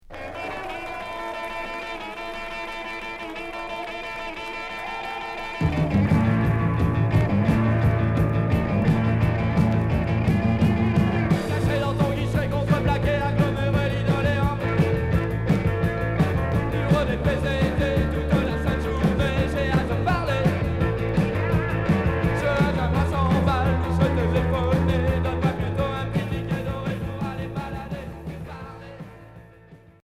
live au bus Palladium
Rock